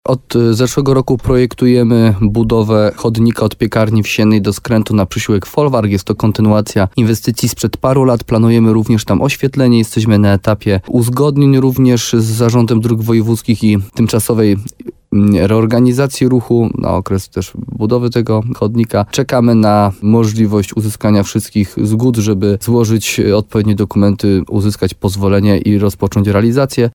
– Przejęcie, na które zgodzili się radni, pozwoli na wybudowanie przez gminę chodnika – tłumaczy wójt Jarosław Baziak.